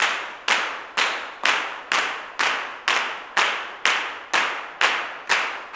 125GCLAPS1-R.wav